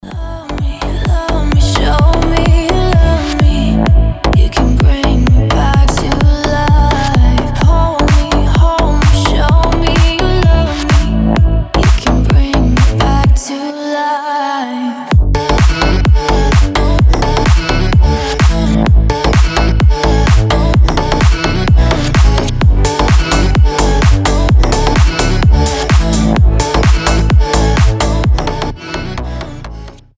• Качество: 320, Stereo
женский голос
EDM
басы
slap house